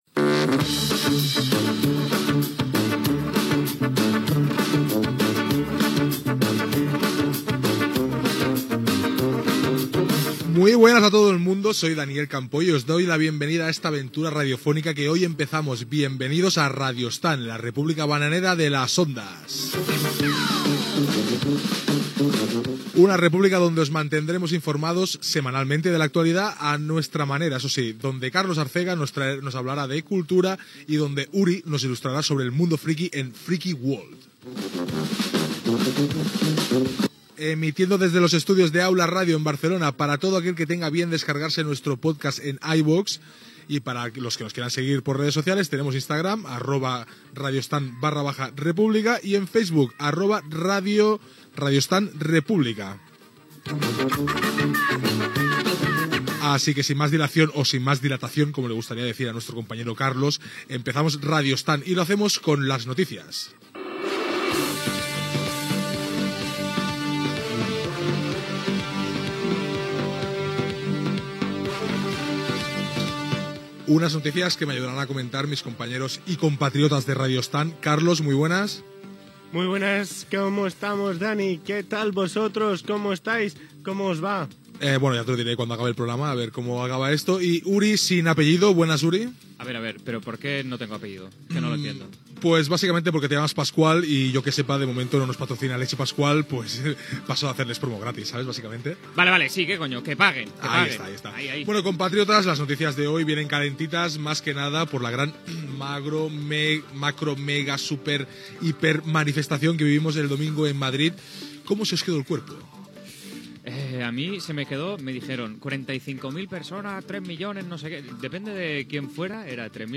Presentació del programa amb identificació.
Entreteniment